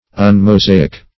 Search Result for " un-mosaic" : The Collaborative International Dictionary of English v.0.48: Un-Mosaic \Un`-Mo*sa"ic\, a. Not according to Moses; unlike Moses or his works.
un-mosaic.mp3